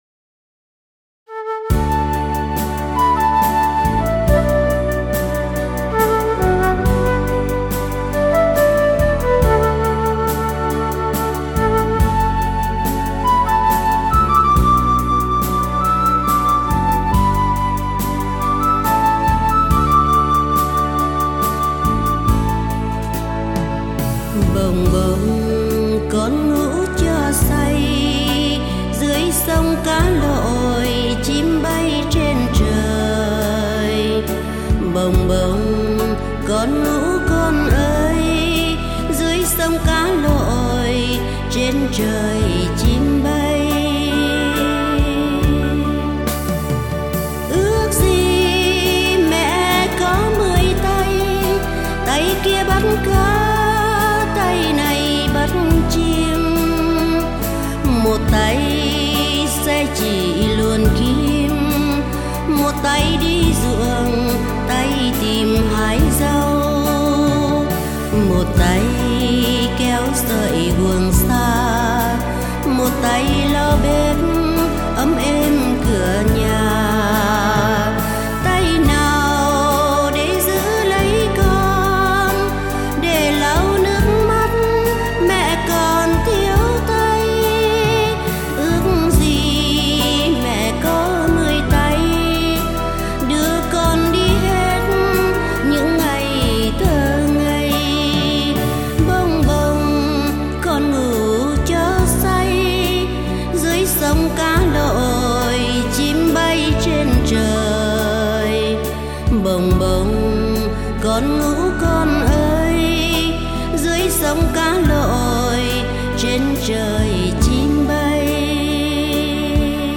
Lời : Bài hát Ru Con